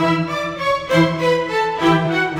Rock-Pop 20 Strings 03.wav